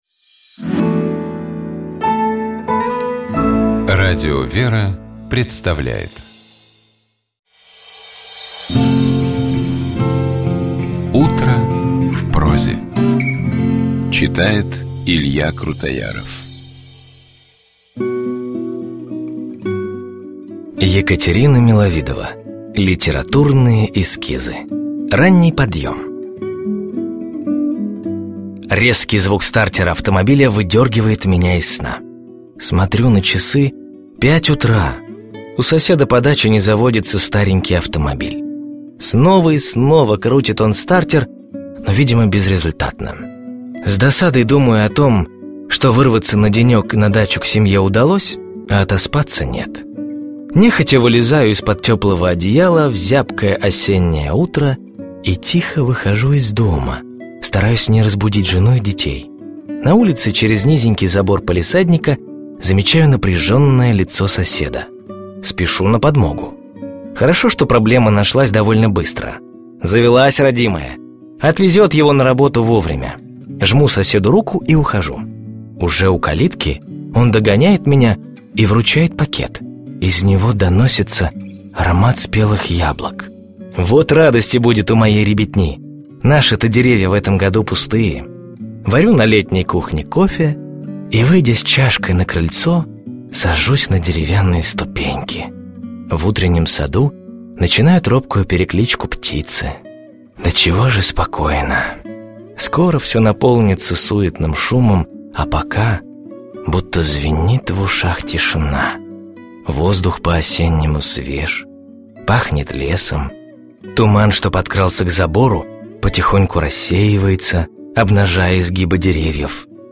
На проповеди после богослужения Предстоятель Русской Православной Церкви говорил о молитве и посте.
В Прощёное воскресение, 22 февраля, Святейший Патриарх Московский и всея Руси Кирилл совершил вечерню с чином прощения в Храме Христа Спасителя в Москве.